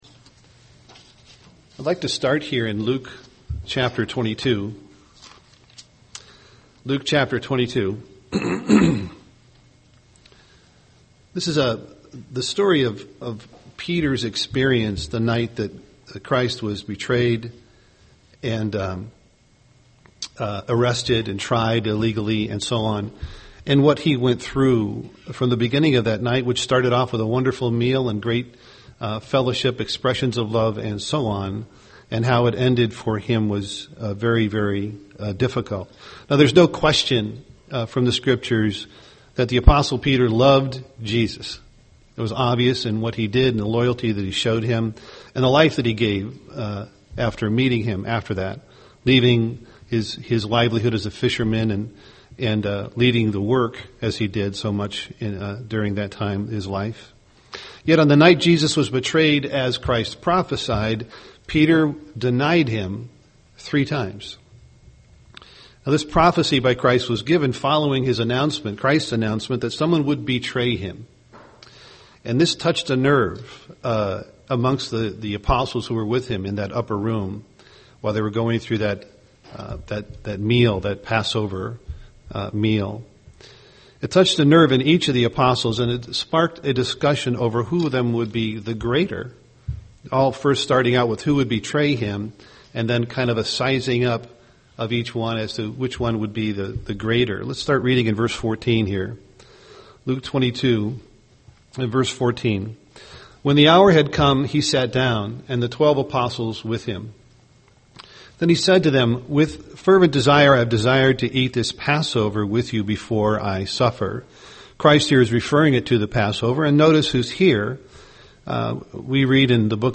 UCG Sermon God is Love Studying the bible?